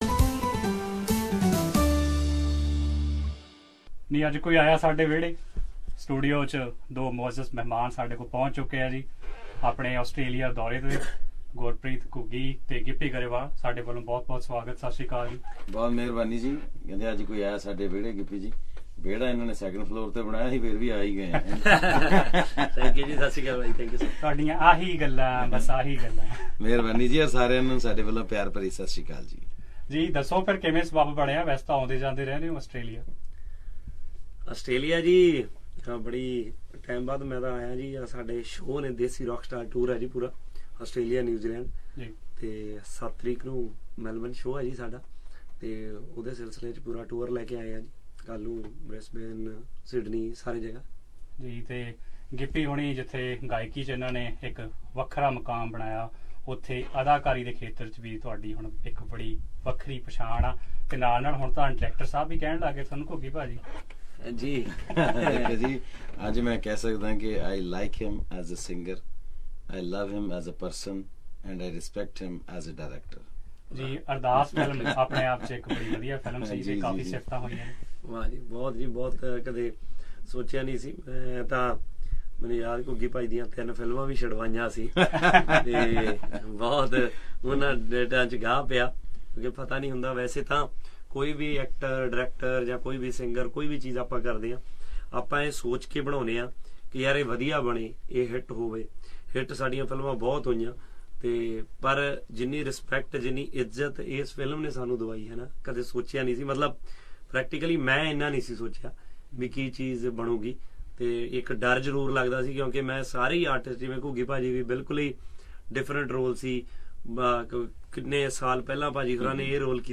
At Melbourne Radio and TV studio Source